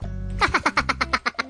Kategori Sjove